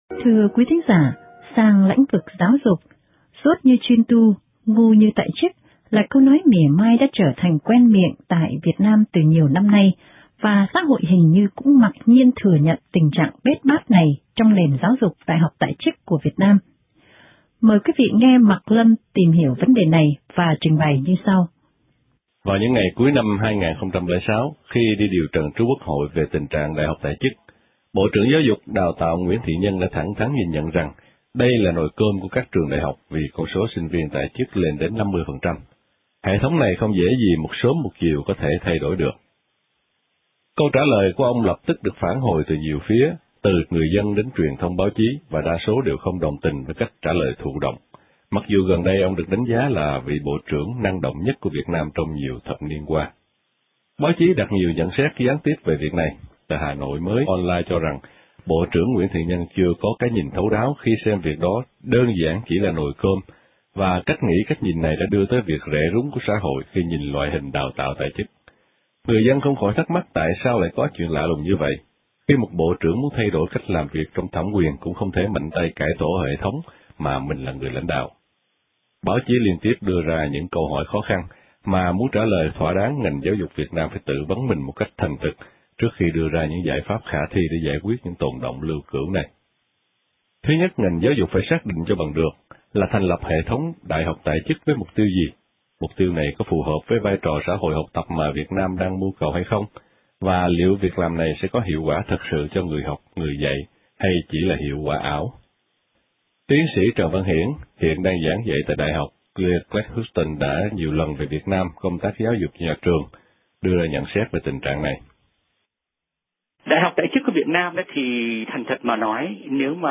Chúng tôi hỏi chuyện Giáo Sư Hoàng Tụy hiện đang giảng dạy trong nước về những suy nghĩ của ông trước những câu hỏi có tính thời sự này.